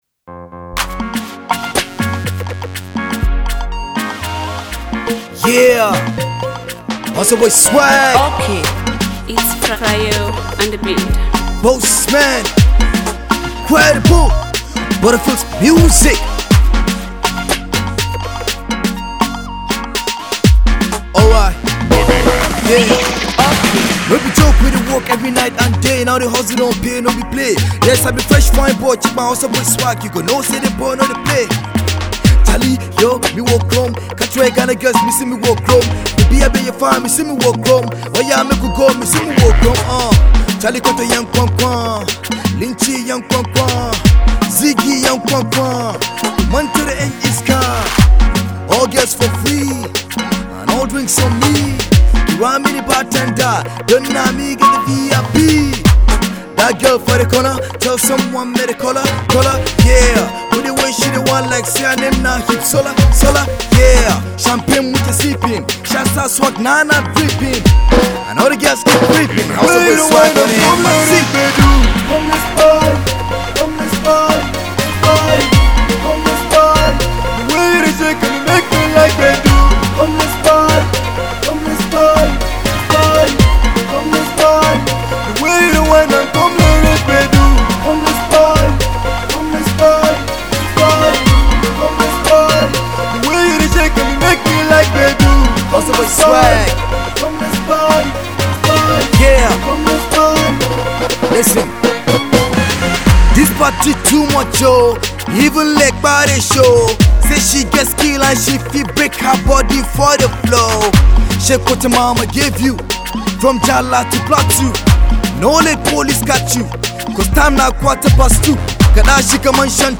danceable tune